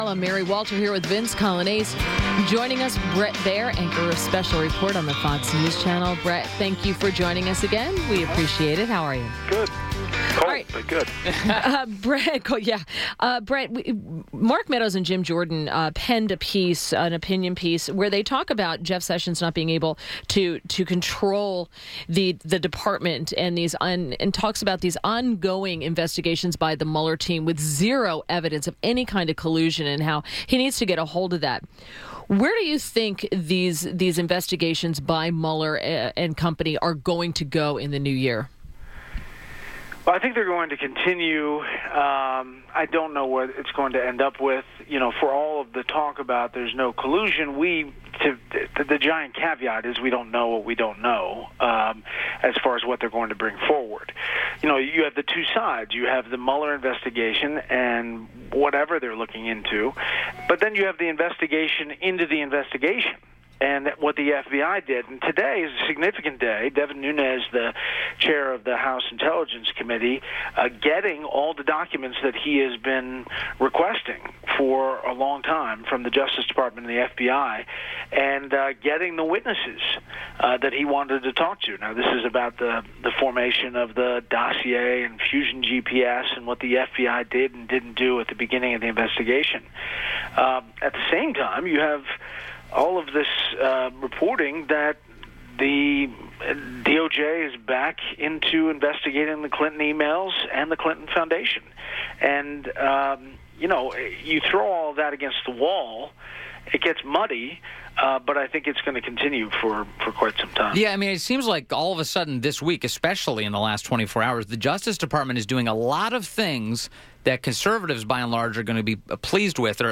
INTERVIEW - BRET BAIER - ANCHOR, SPECIAL REPORT, FOX NEWS CHANNEL